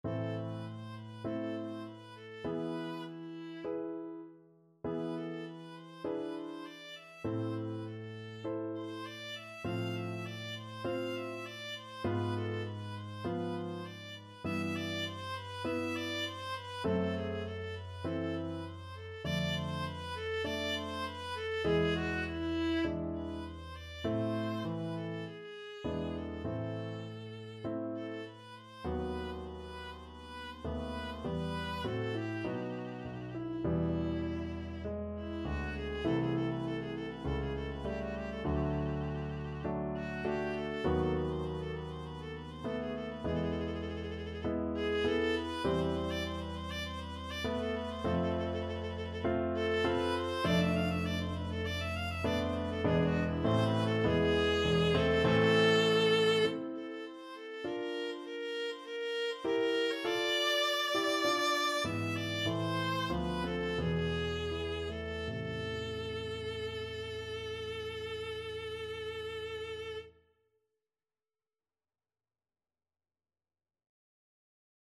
4/4 (View more 4/4 Music)
E5-F6
Largo
Classical (View more Classical Viola Music)